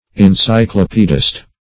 Encyclopedist \En*cy`clo*pe"dist\, n. [Cf. F.